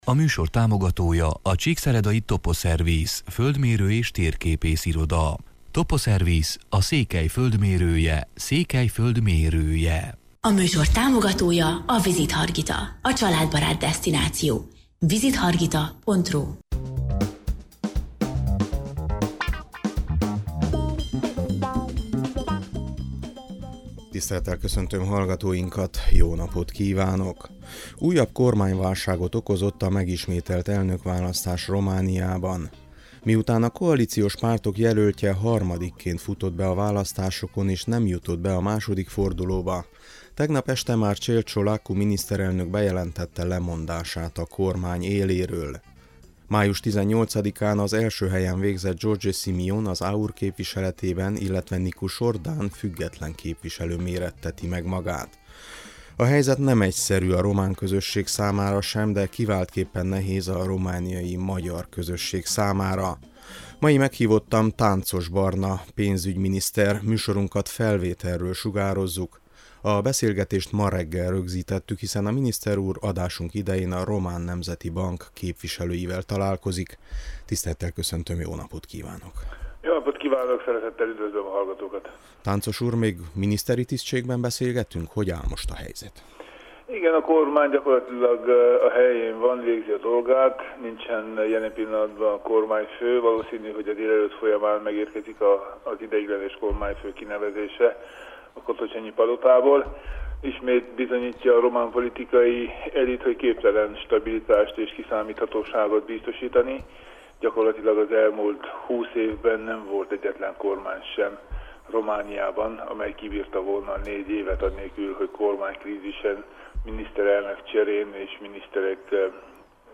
Mai meghívottam Tánczos Barna pénzügyminiszter: